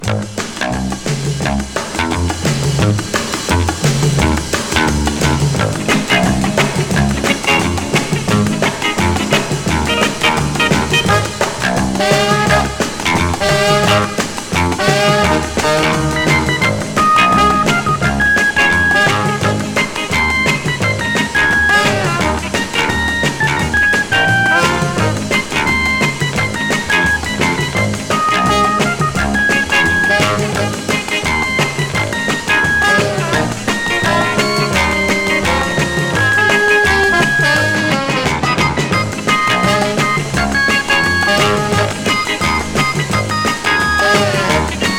Jazz, Rock, Pop, Lounge　USA　12inchレコード　33rpm　Mono